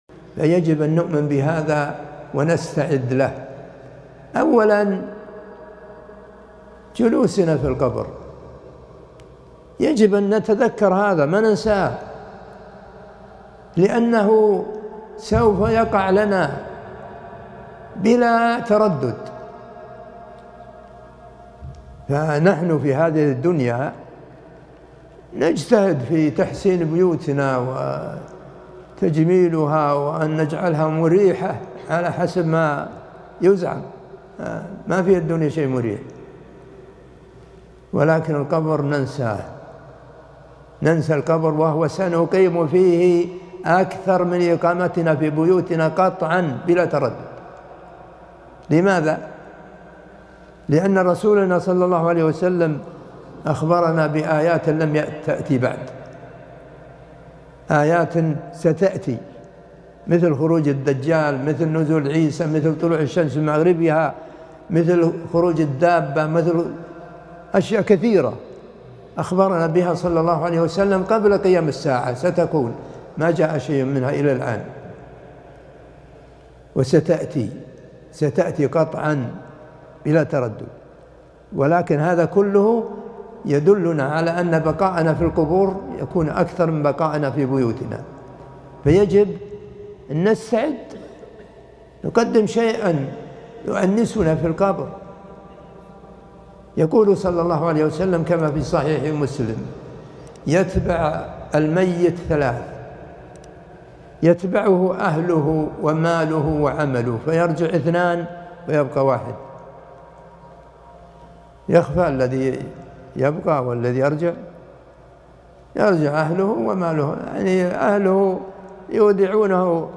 مقتطف موعظة - تجهز واستعد لجلوسك في قبرك